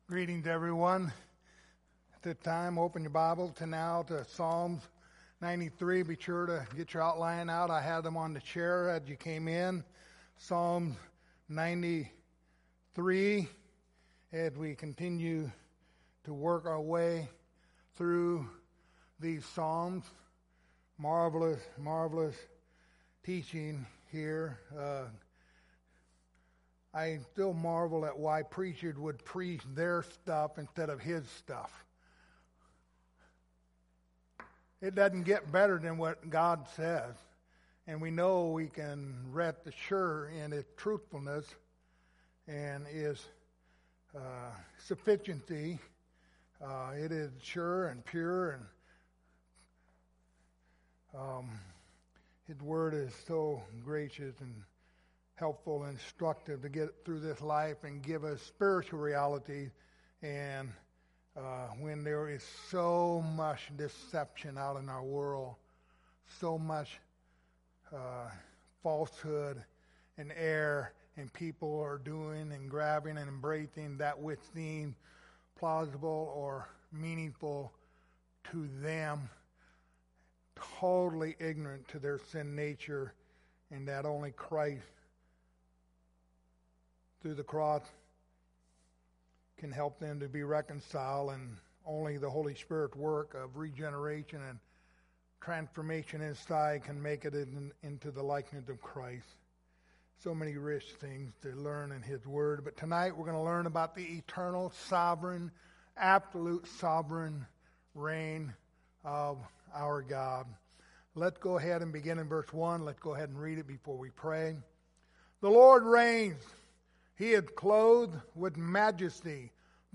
The book of Psalms Passage: Psalms 93:1-5 Service Type: Sunday Evening Topics